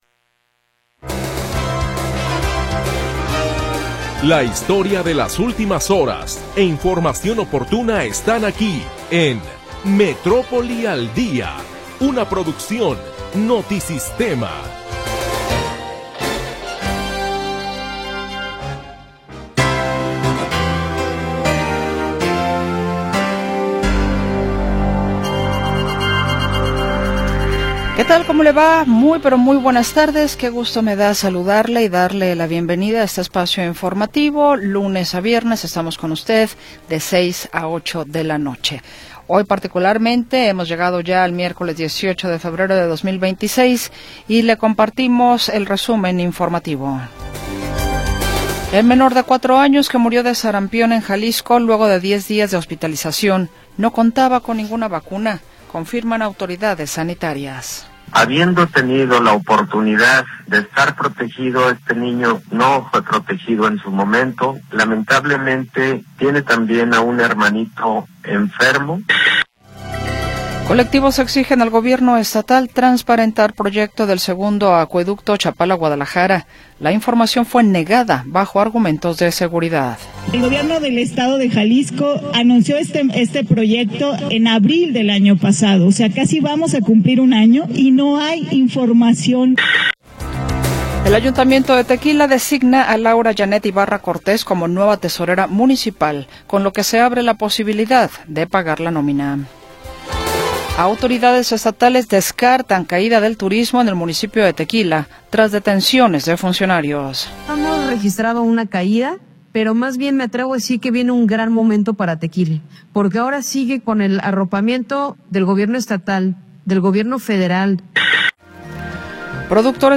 Primera hora del programa transmitido el 18 de Febrero de 2026.